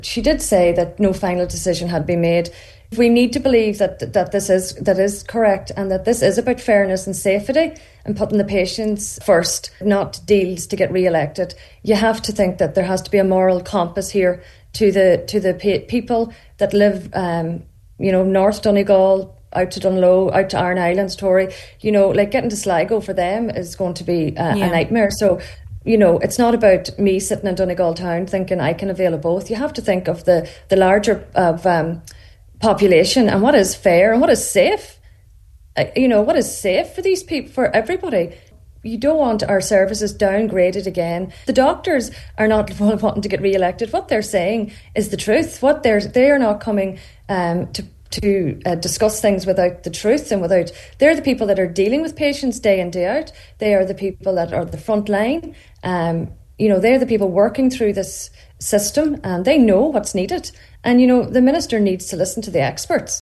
During the Friday panel on today’s Nine til Noon Show